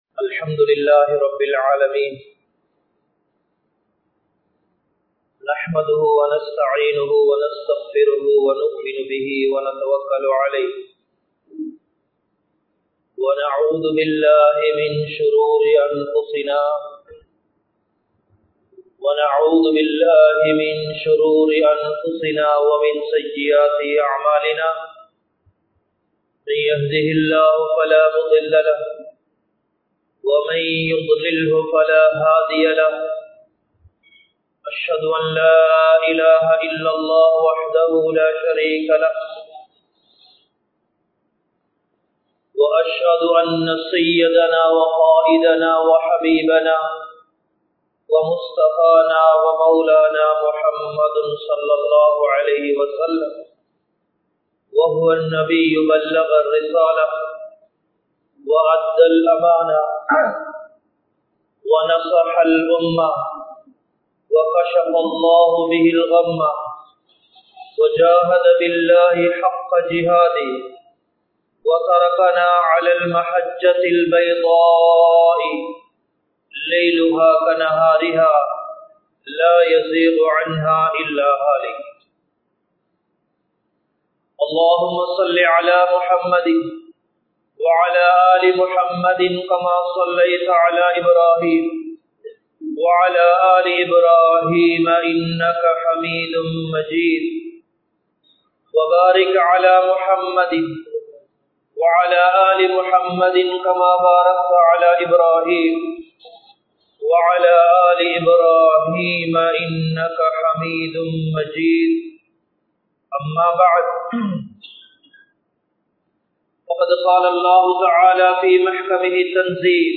Soathanaihalum Allah vin Uthavium (சோதனைகளும் அல்லாஹ்வின் உதவியும்) | Audio Bayans | All Ceylon Muslim Youth Community | Addalaichenai